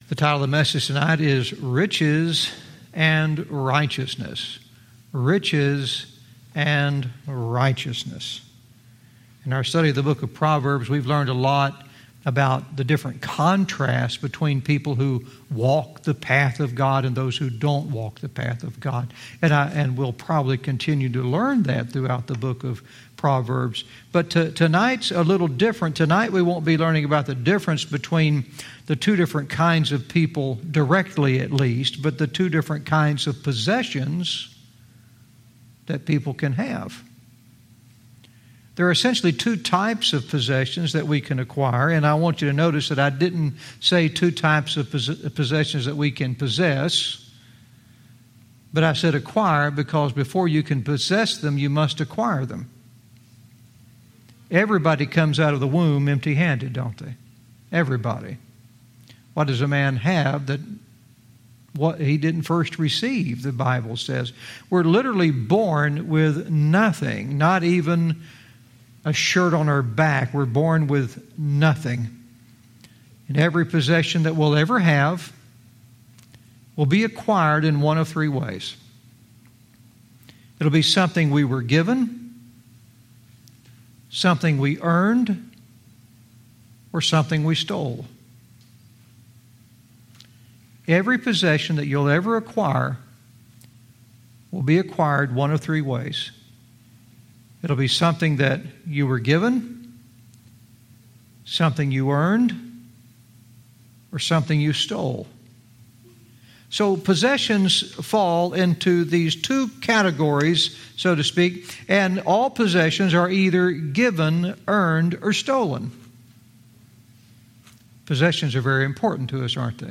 Verse by verse teaching - Proverbs 11:4 "Riches & Righteousness"